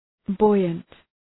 Shkrimi fonetik {‘bɔıənt}
buoyant.mp3